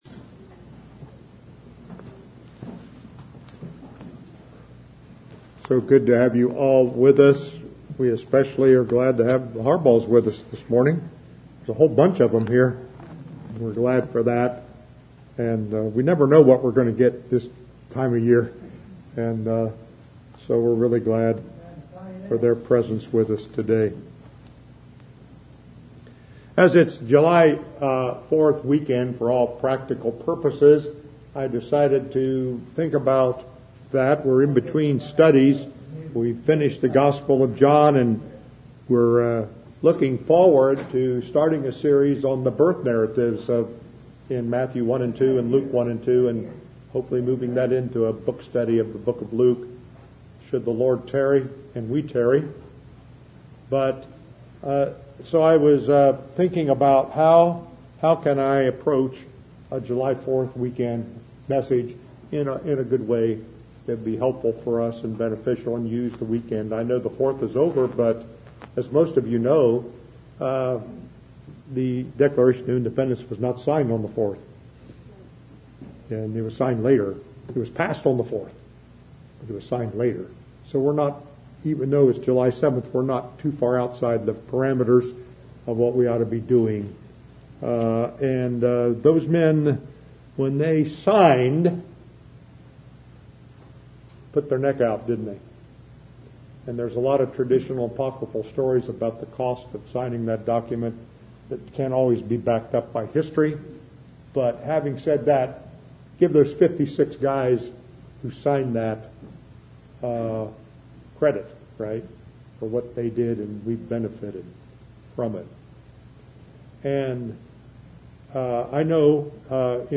2019 Downloadable Sermon Archive